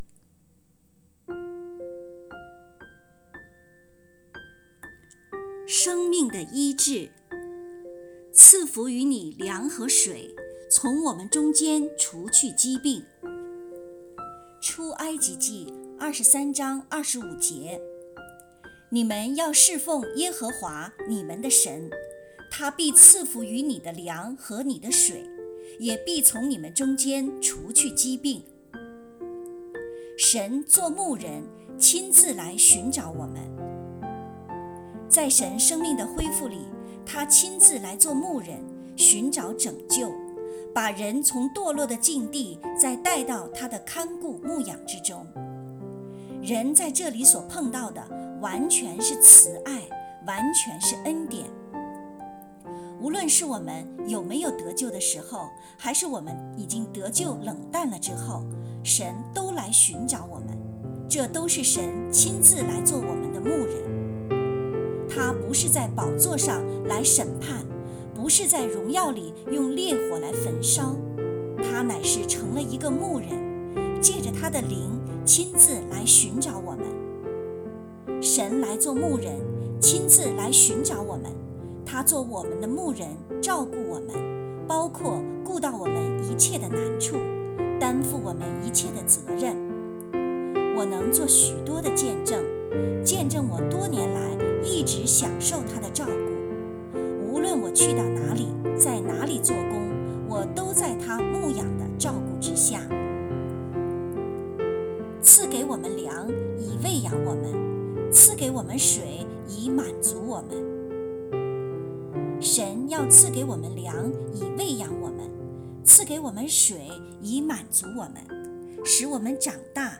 有声版